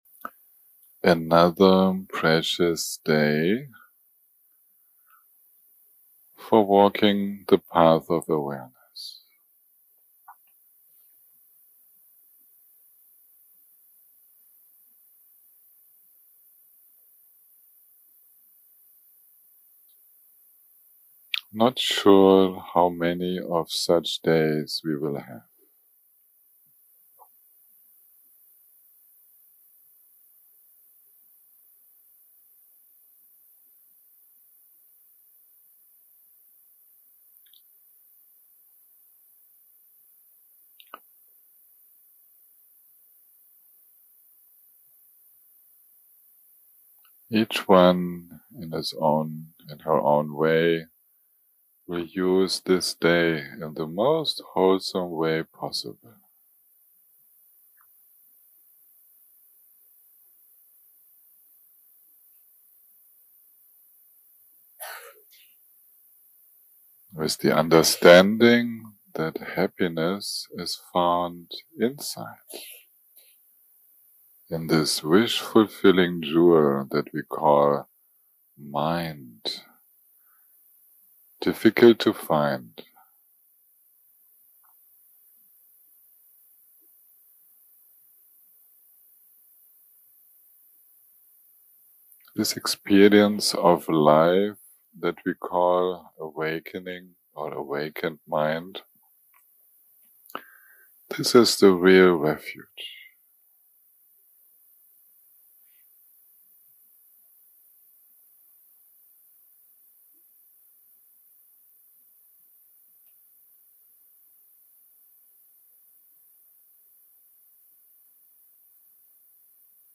day 7 - recording 22 - Early Morning - Guided Meditation + Chanting - Vajrasatva
day 7 - recording 22 - Early Morning - Guided Meditation + Chanting - Vajrasatva 0:00 0:00 סוג ההקלטה: Dharma type: Guided meditation שפת ההקלטה: Dharma talk language: English